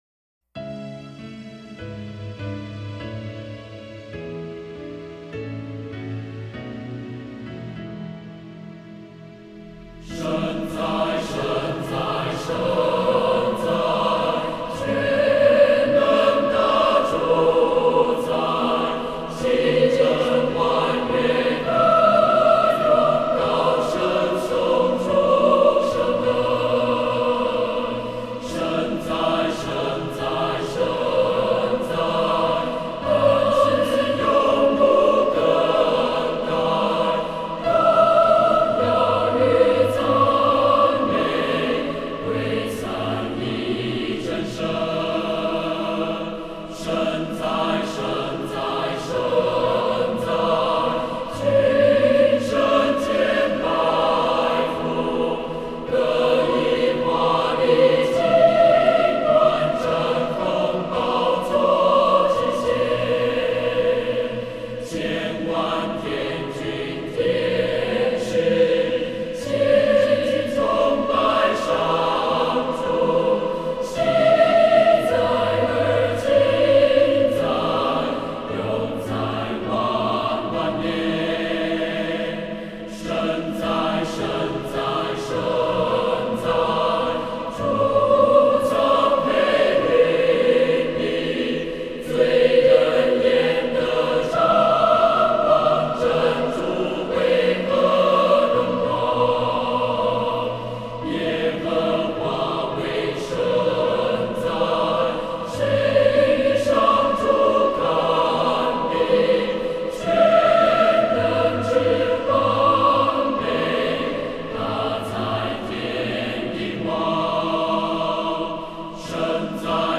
是一首基督教赞美诗